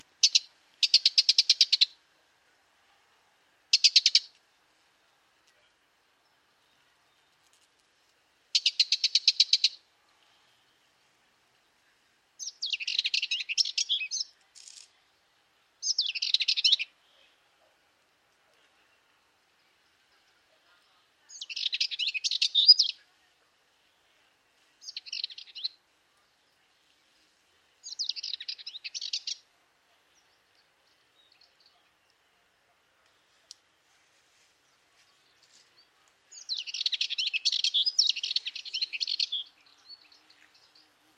fauvette-melanocephale.mp3